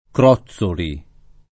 [ kr 0ZZ oli ]